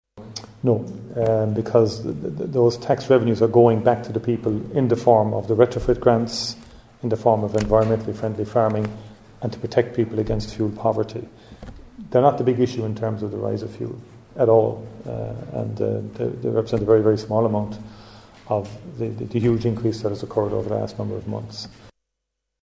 He was speaking as the Dáil debated a motion from Sinn Féin last night calling for the planned rises this year to be deferred.
The Taoiseach was asked if that means they’ll consider deferring carbon tax increases: